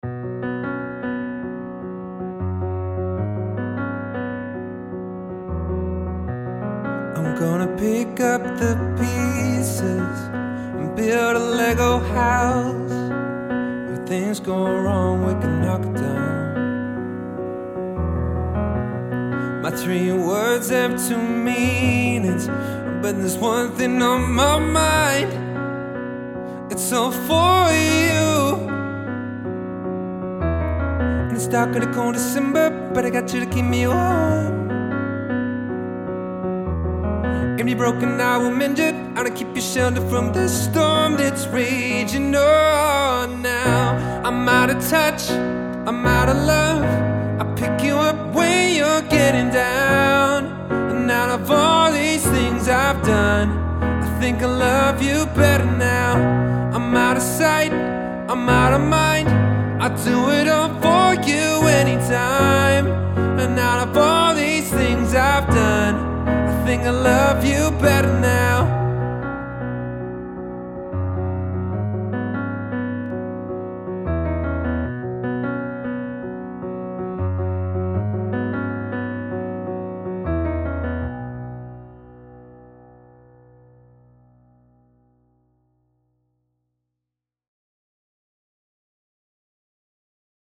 Vocals | Keyboards | Looping | DJ MC